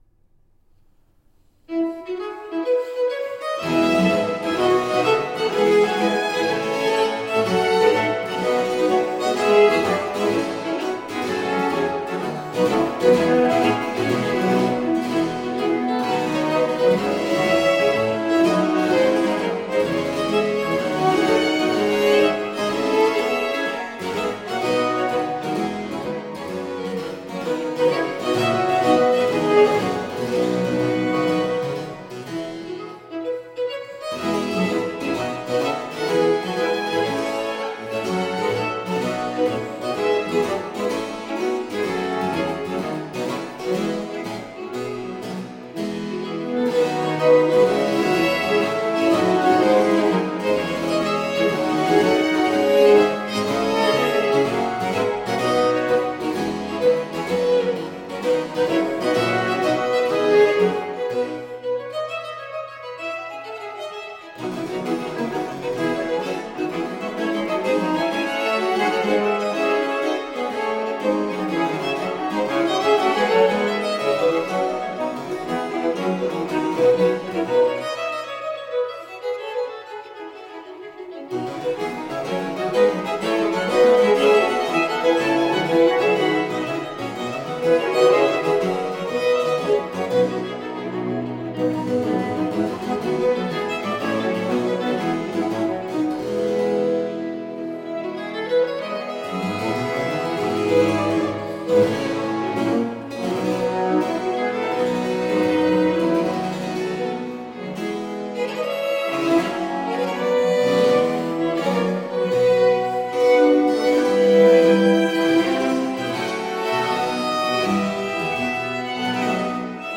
Baroque instrumental and vocal gems.